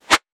weapon_bullet_flyby_24.wav